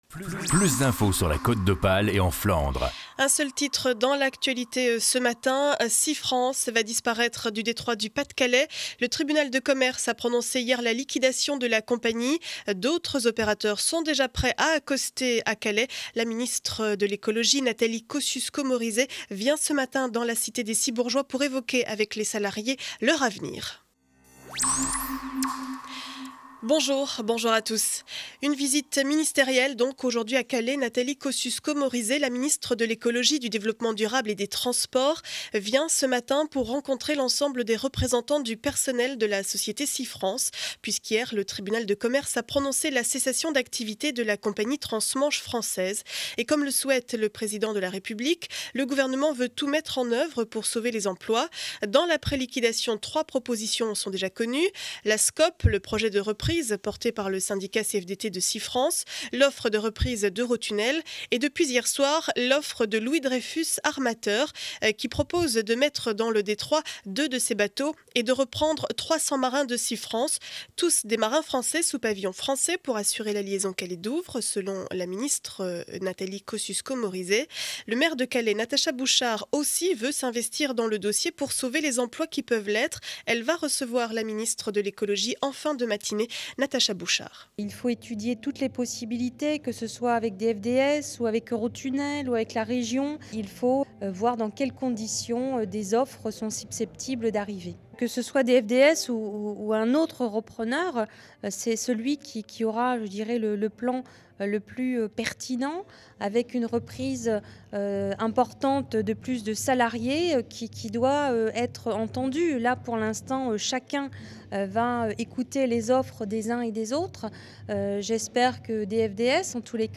Journal du mardi 10 janvier 7 heures 30 édition du Calaisis